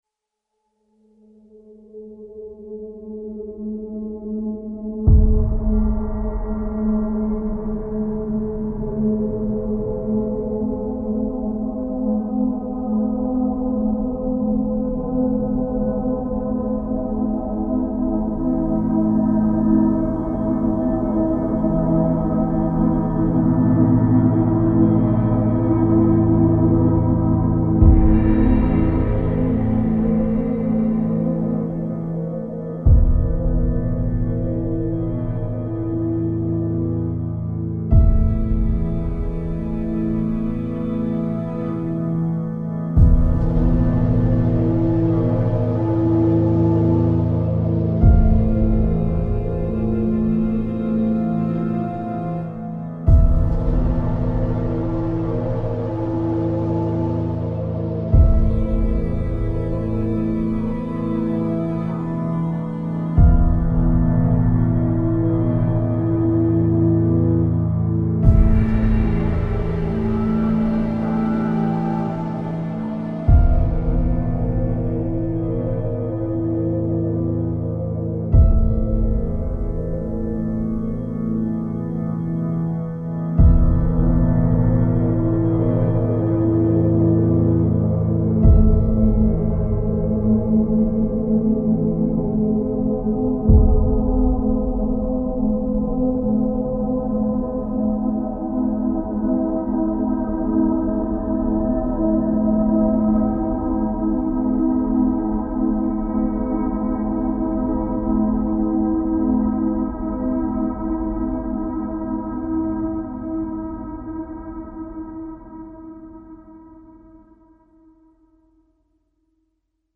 Creepy ambo.